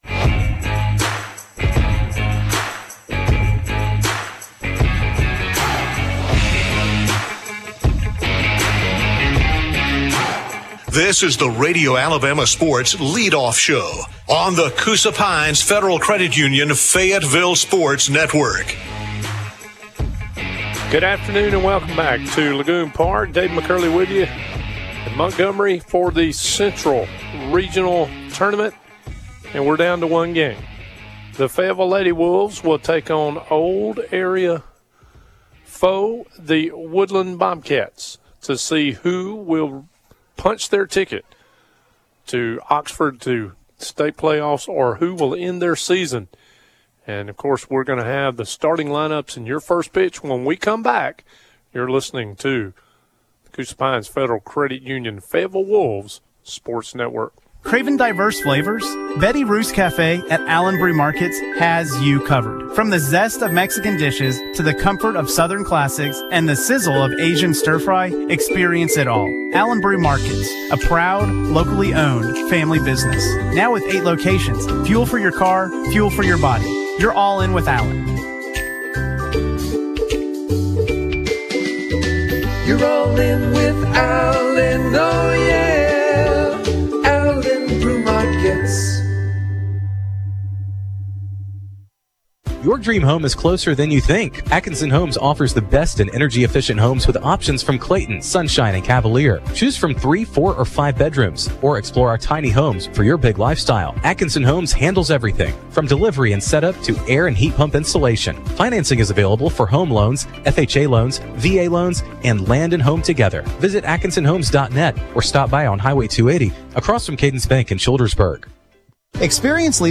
call Fayetteville's game against Woodland in Game 4 of the Regional Tournament. The Wolves lost 11-0.